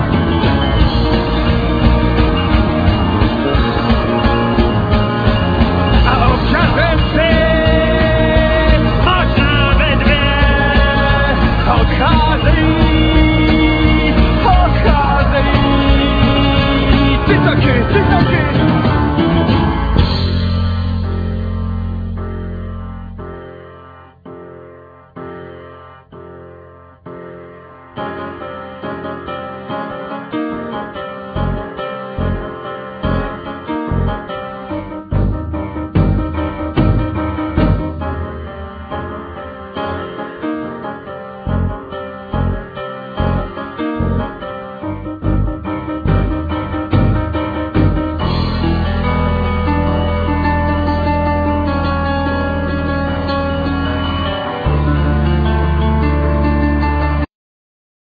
Vocal,Piano
Drums
Bass